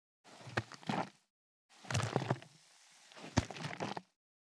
345ジャブジャブ,シュワシュワ,プシュッ,シュッ,ドクドク,ポン,バシャ,ブルブル,ボコボコ,
ペットボトル